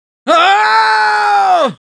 Spy_paincrticialdeath02_es.wav